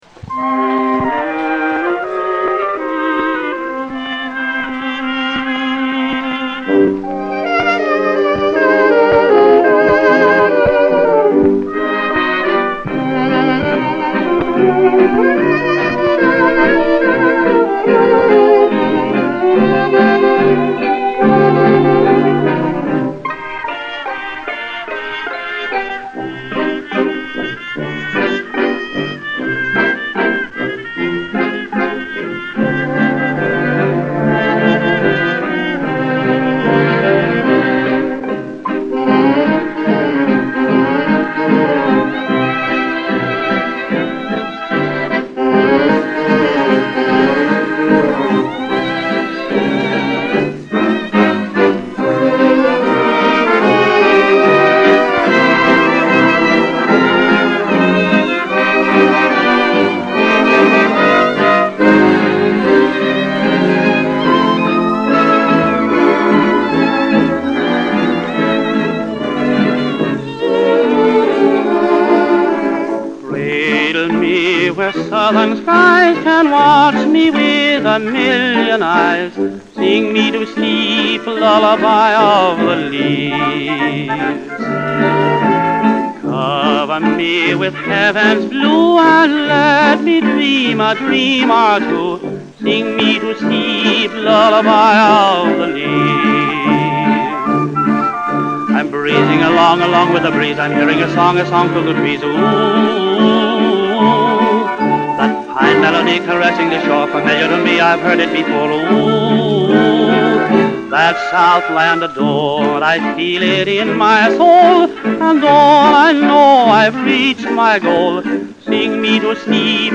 2 tracks on one side of a floppy cardboard record.